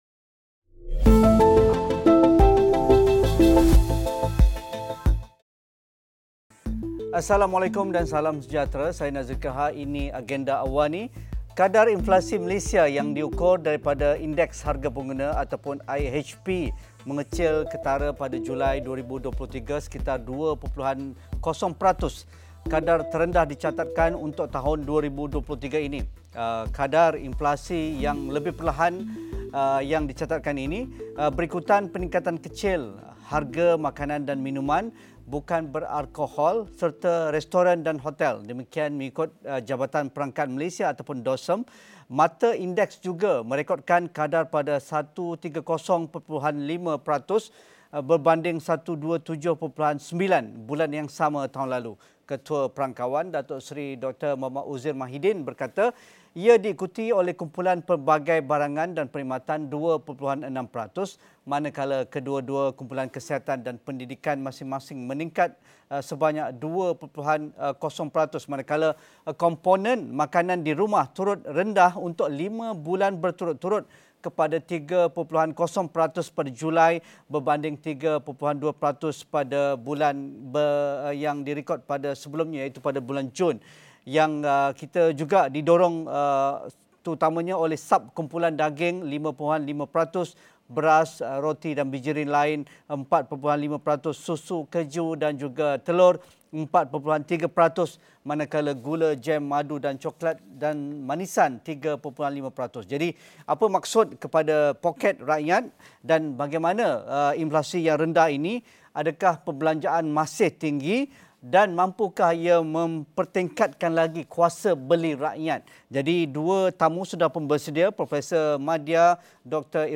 Inflasi Julai 2023 mereda pada 2.0 peratus namun sejauh mana ia refleksi kepada harga barang dan makanan? Memastikan risiko gangguan bekalan makanan, apa usaha untuk elak monopoli dalam pasaran? Diskusi 8.30 malam